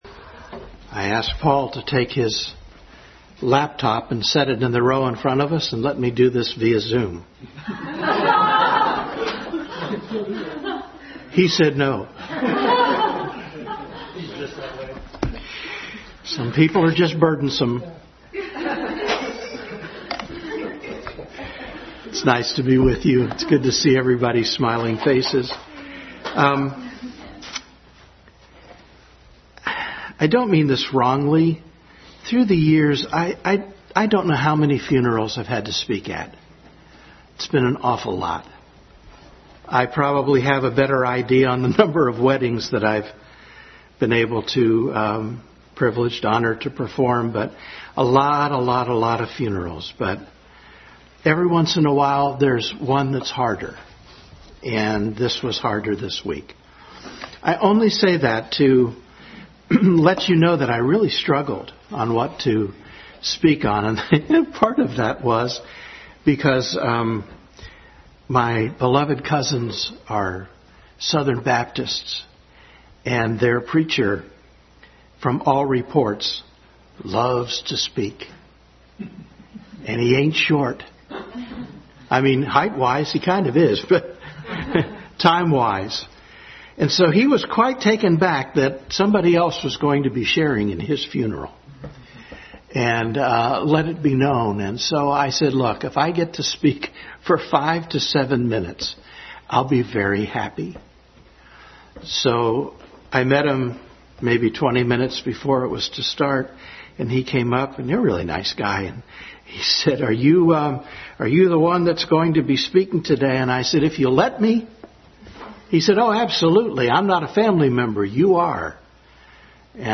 Hope Passage: 1 Corinthians 15:19, 1 Thessalonians 4:13-18, Titus 2:13 Service Type: Family Bible Hour Family Bible Hour Message.